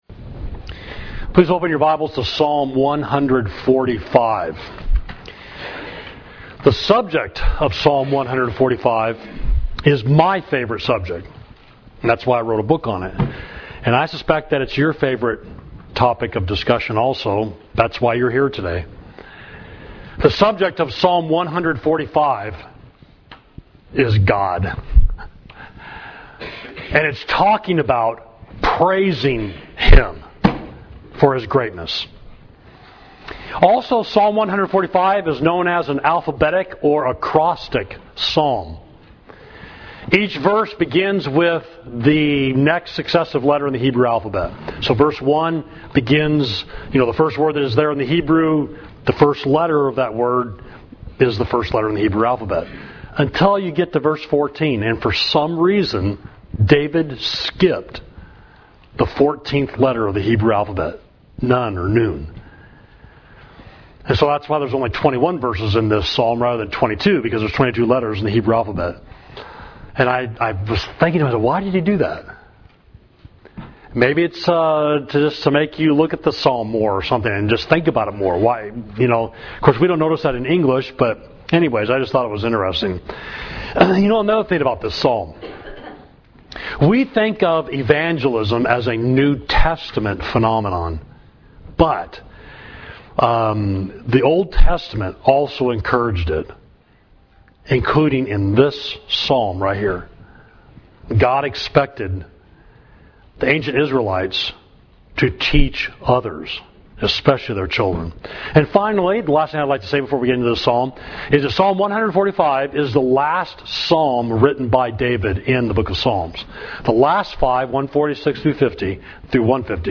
Sermon: Great Is the Lord, Psalm 145